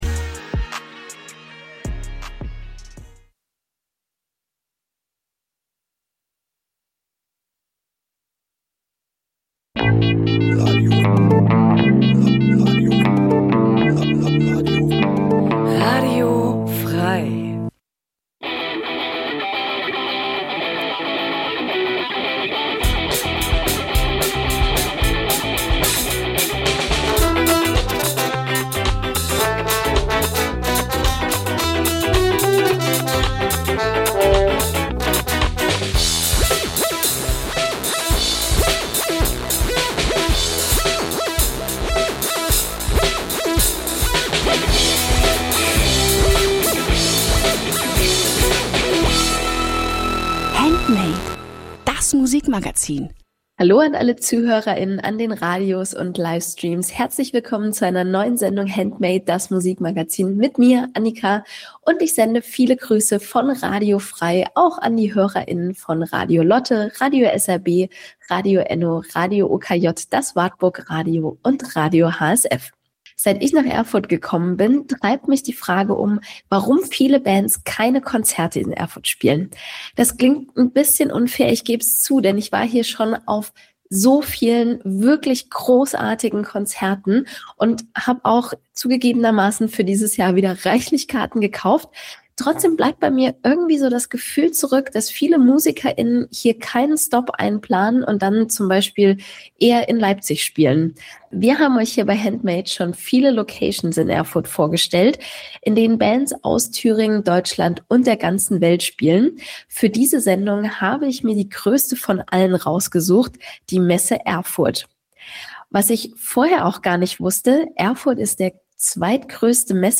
Jeden Donnerstag stellen wir euch regionale Musik vor und scheren uns dabei nicht um Genregrenzen.
Wir laden Bands live ins Studio von Radio F.R.E.I. ein, treffen sie bei Homesessions oder auf Festivals.